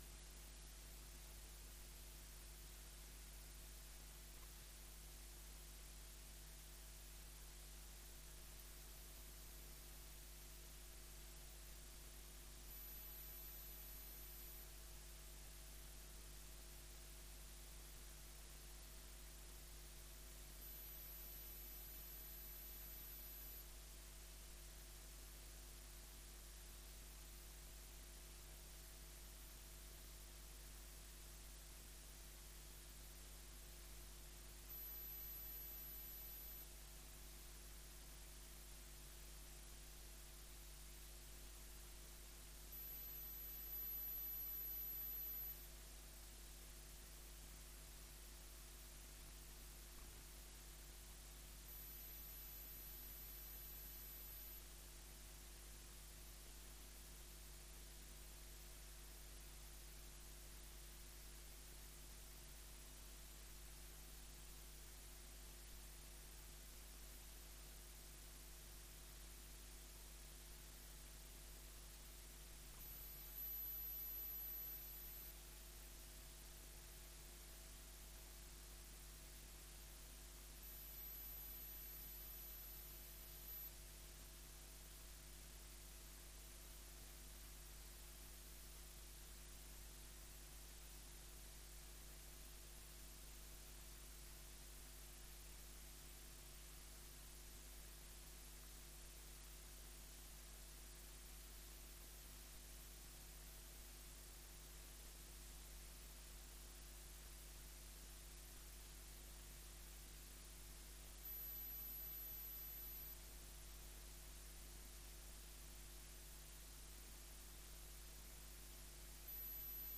Enlace a Presentación de la Feria Región de Murcia Gastronómica.
Estas son algunas de las propuestas de la cocina local que se podrán degustar en el stand que el Ayuntamiento abre al público en esta feria que se celebra en el Auditorio Regional Víctor Villegas (Murcia) del 8 al 11 de noviembre. La comitiva de Cartagena ha sido presentada este jueves, 7 de noviembre, en el Palacio Consistorial en un acto que ha contado con la alcaldesa Noelia Arroyo; la edil de Comercio, Belén Romero; el director de negocio de La